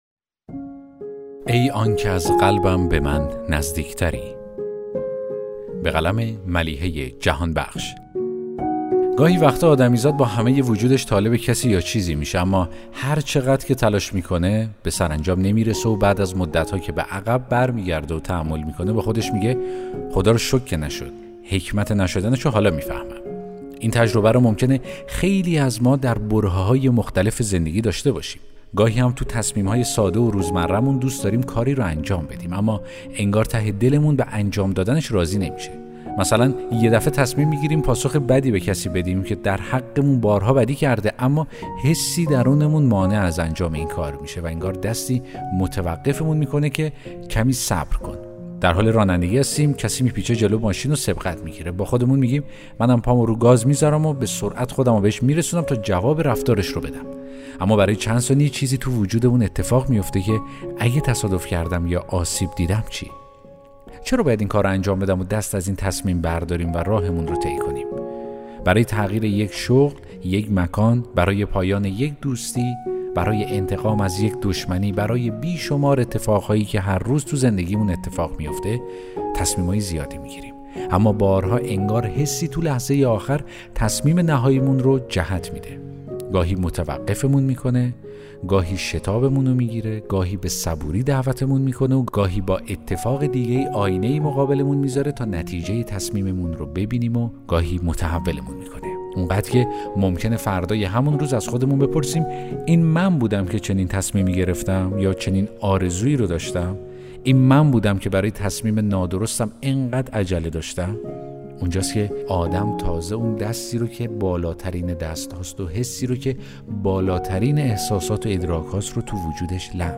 داستان صوتی: ای آنکه از قلبم به من نزدیک تری!